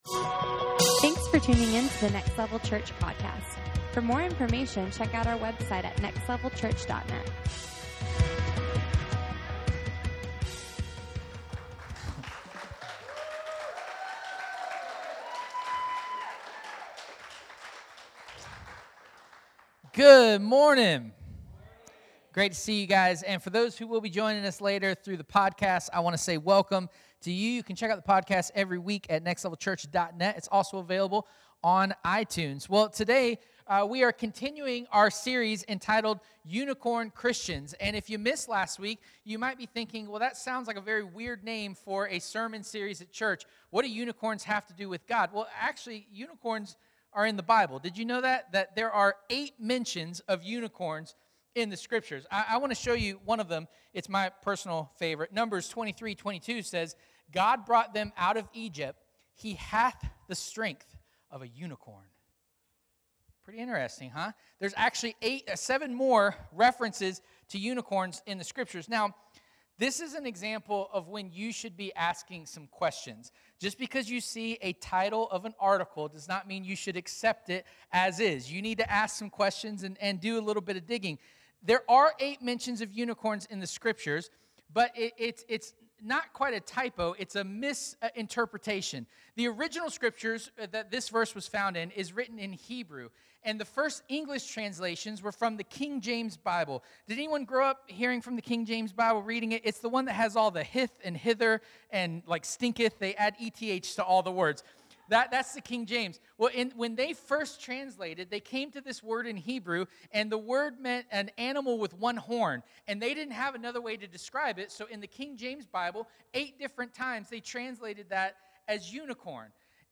Unicorn Christians Service Type: Sunday Morning Watch A unicorn is a mythical creature.